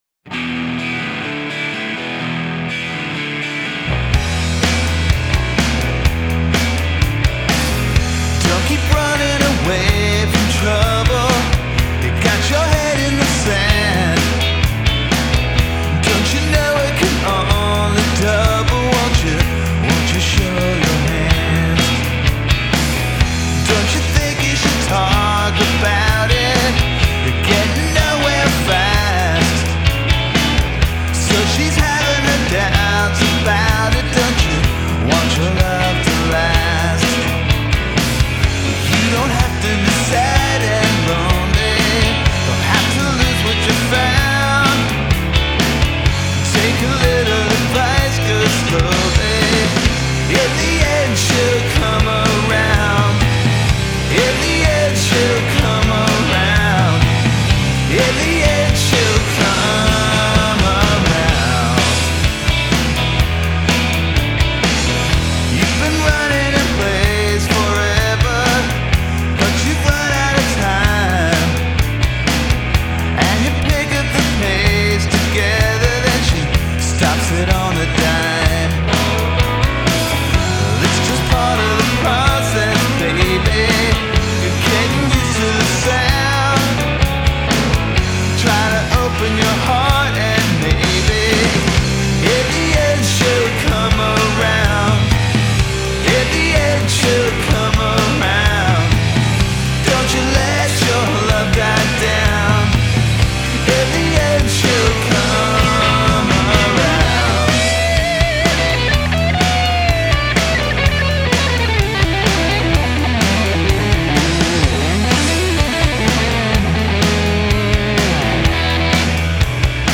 Tempo 126 BPM
Key E